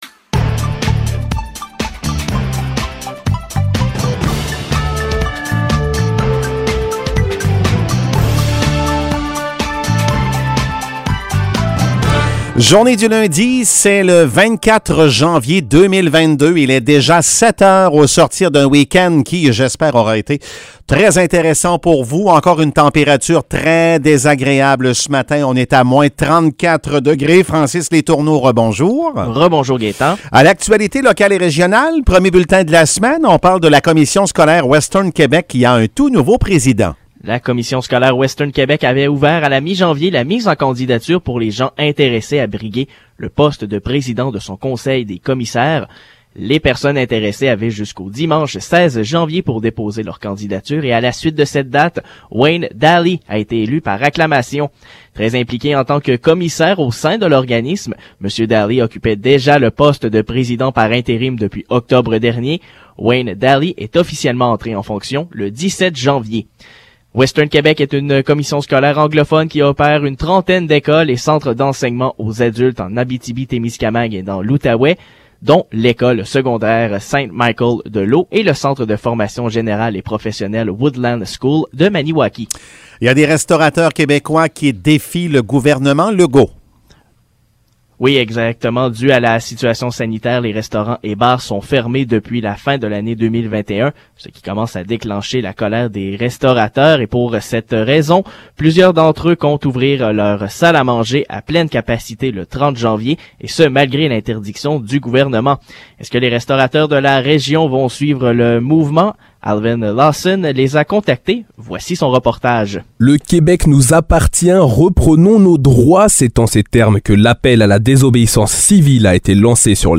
Nouvelles locales - 24 janvier 2022 - 7 h